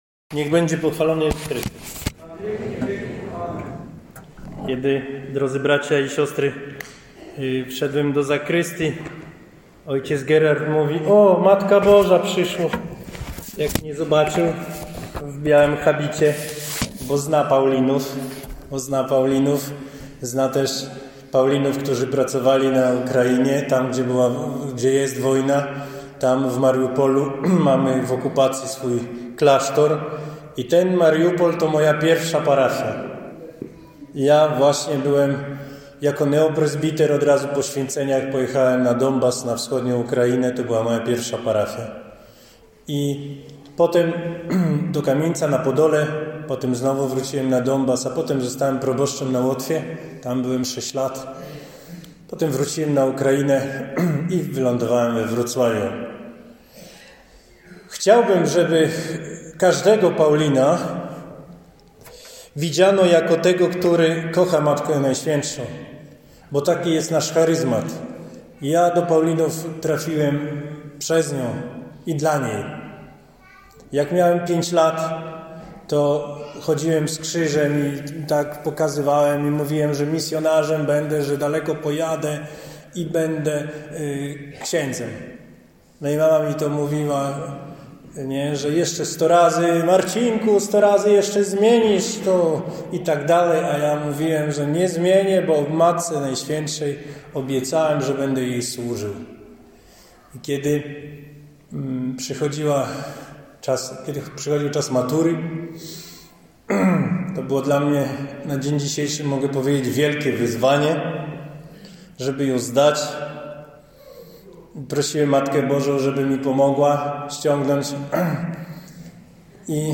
Nauki rekolekcyjni do odsłuchania.
nauka-rekolekcyjna-godz-13.mp3